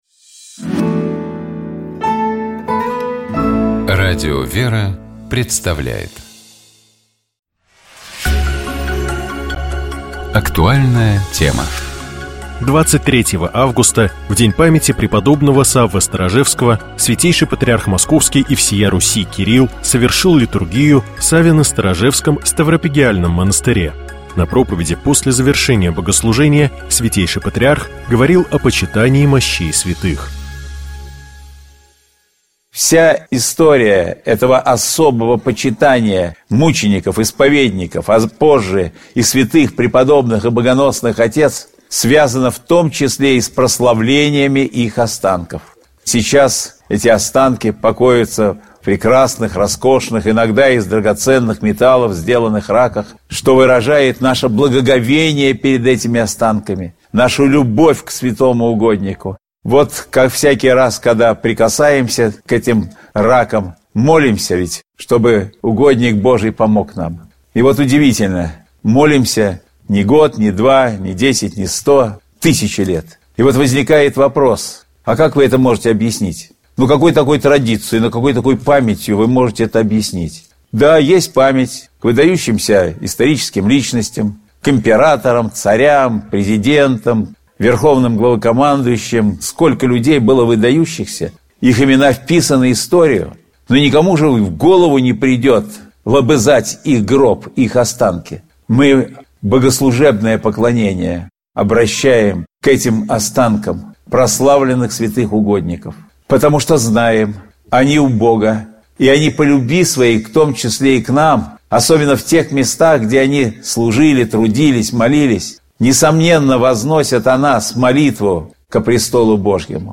В этом выпуске участники беседы делились светлыми, радостными, забавными и вдохновляющими историями из церковной жизни, которые вспоминаются с улыбкой.